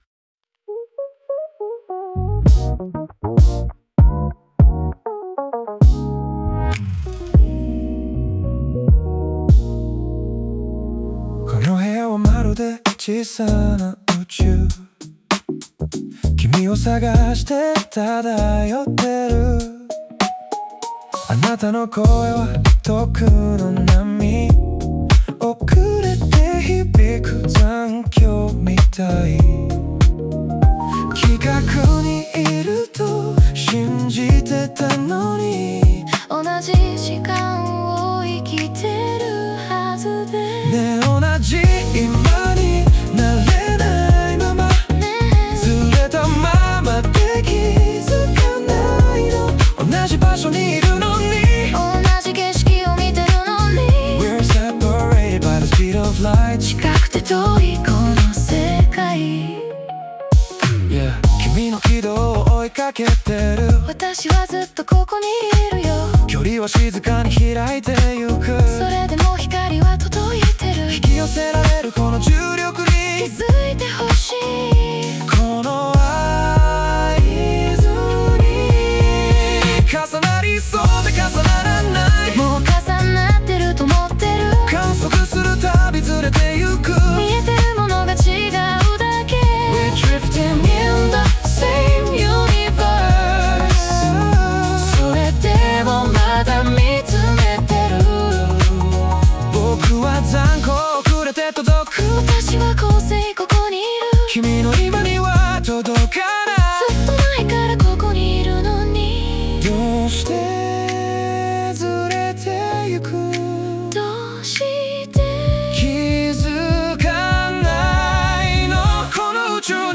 歌ものフリー素材 bgm音楽 無料ダウンロード 商用・非商用ともに登録不要で安心してご利用いただけます。
デュエット
イメージ：メロディックR＆B,ネオソウル,デュエット,夜